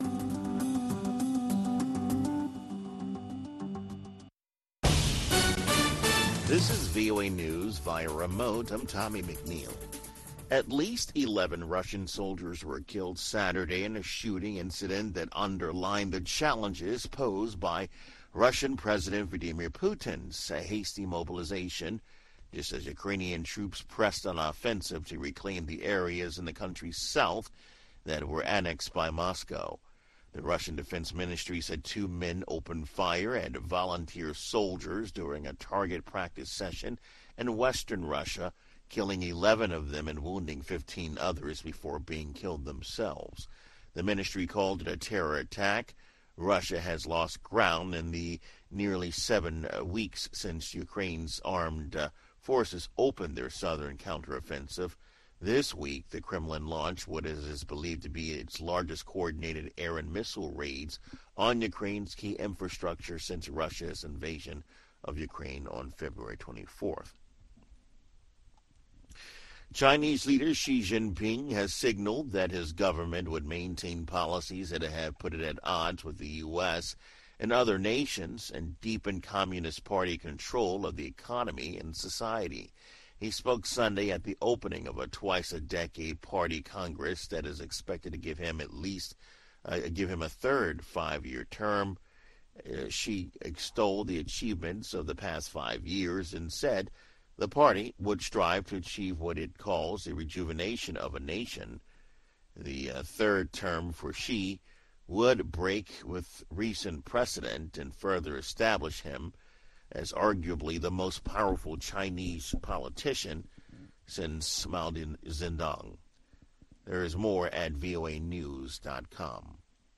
VOA Newscasts - Voice of America: VOA Newscasts